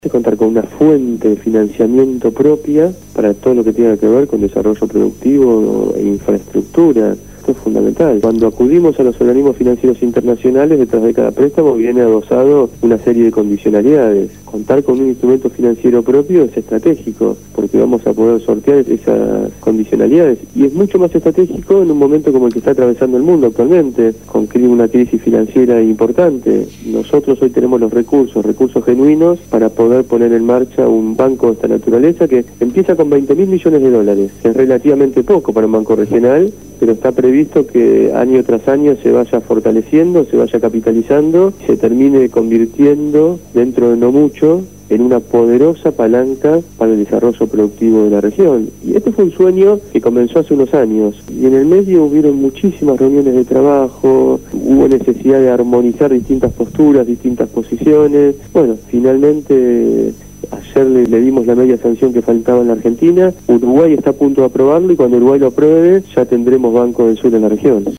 Ariel Pasini se refirió en esta entrevista a la Asignación Universal por Hijo, las jubilaciones, el Banco del Sur, la Ley de Tierras y la economía argentina.